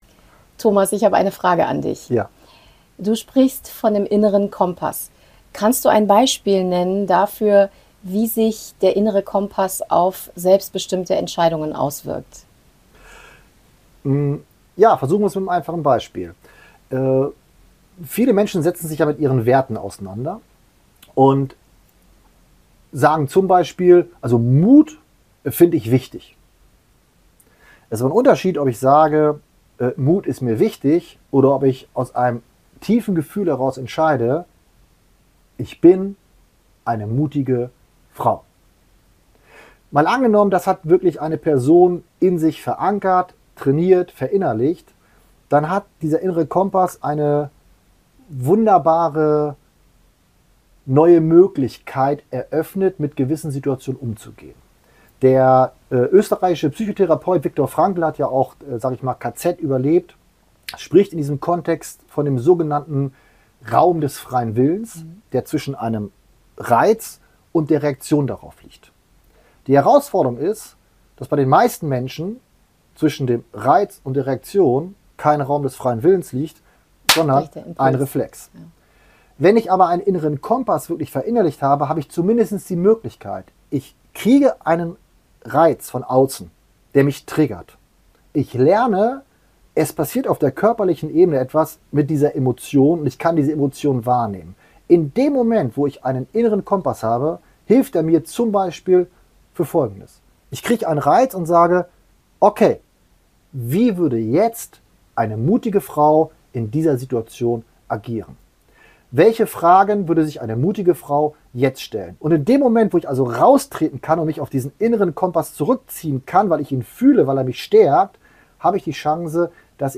im kurzen Gespräch